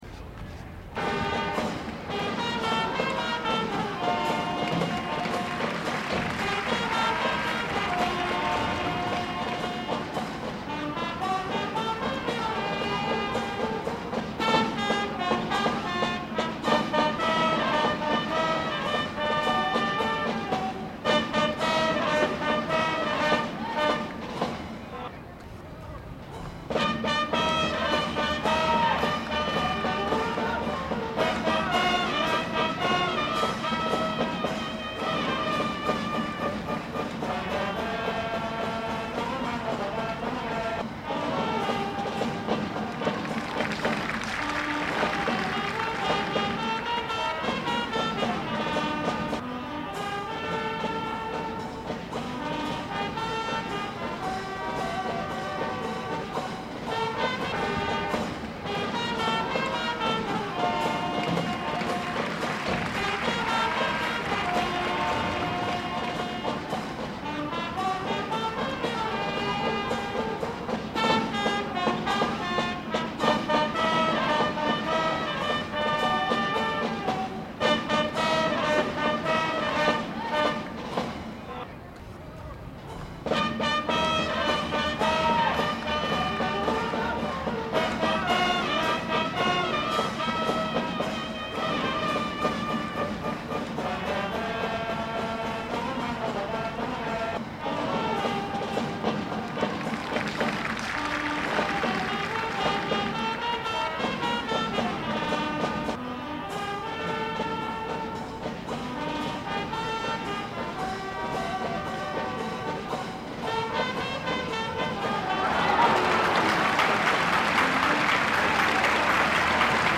Corrida Goyesca
Debe visionar las fotografías con música y con el sonido de ambiente de la corrida